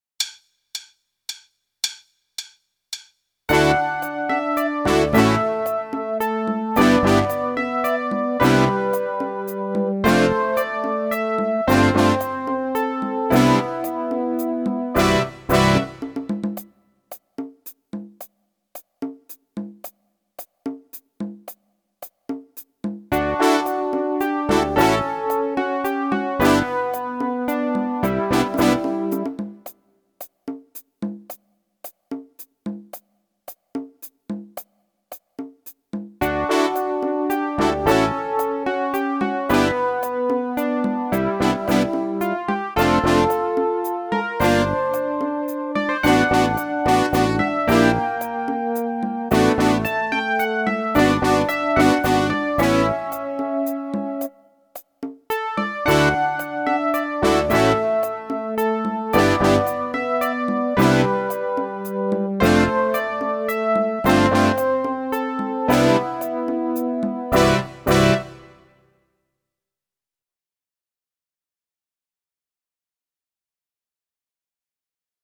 Meespeel CD
9. Solo in een driekwartsmaat